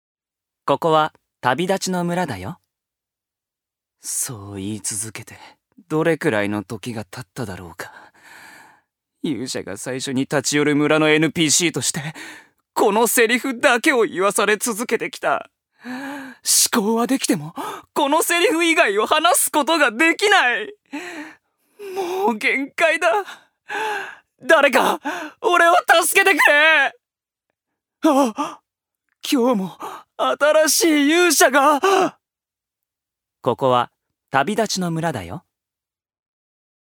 所属：男性タレント
音声サンプル
セリフ６